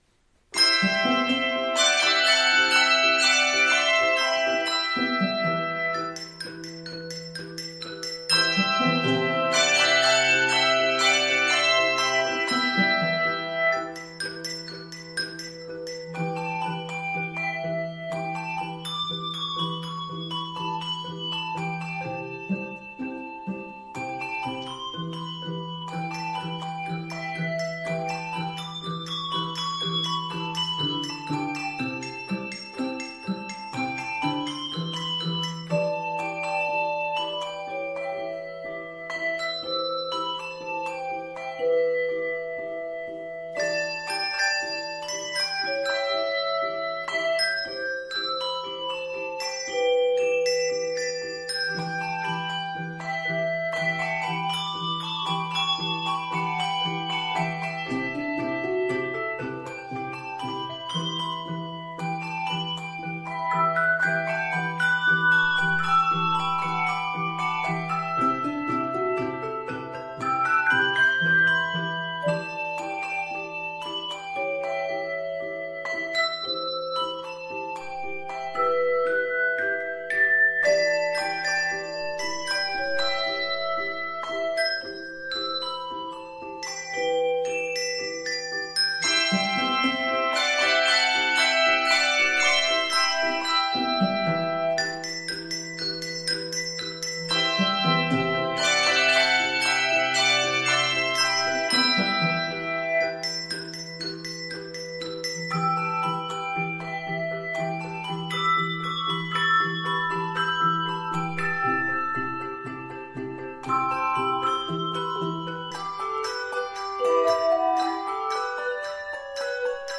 Key of e minor.